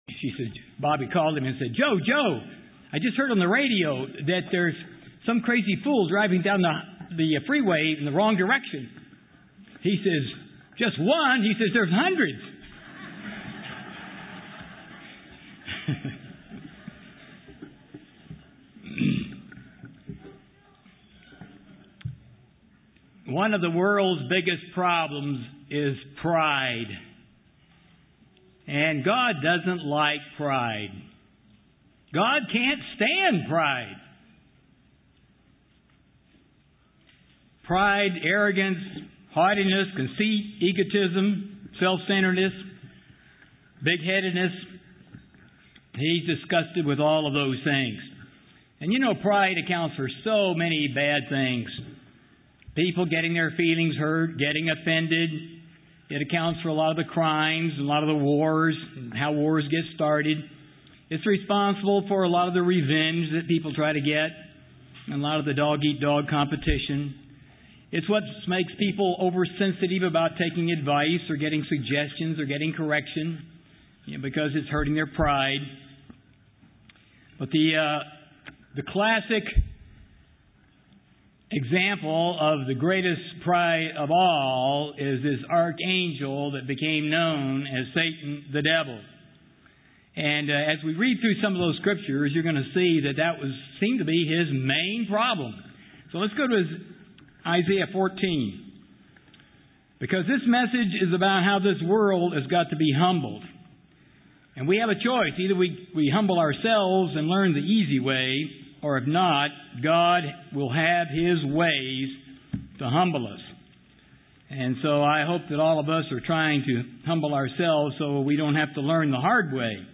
This sermon was given at the Kelowna, British Columbia 2010 Feast site.